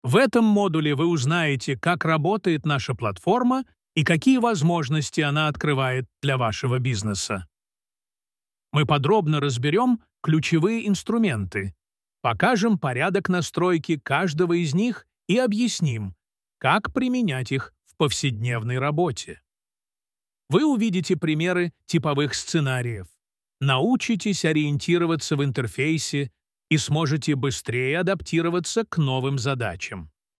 Мужской голос с убедительной подачей и напористостью в некоторых местах.
При первом прослушивании создается впечатление, что в процессе задействован живой диктор, – естественные интонации, ровный темп, отсутствует типичная машинная монотонность.